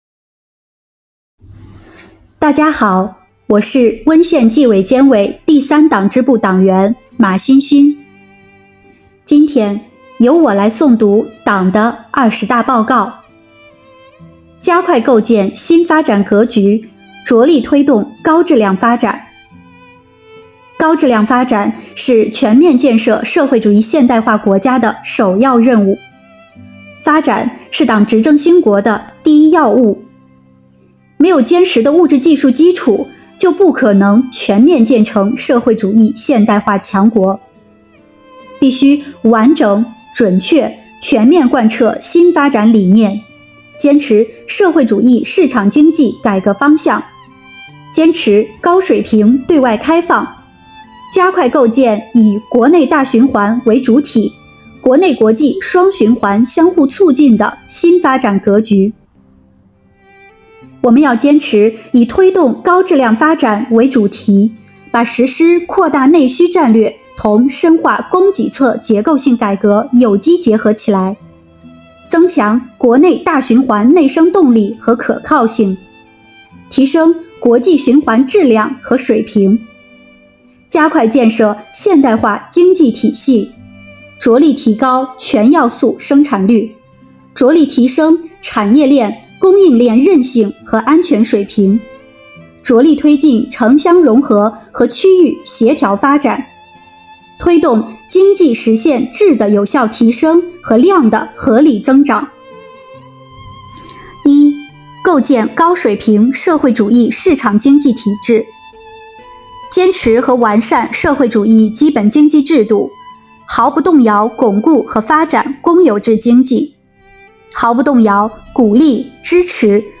本期诵读人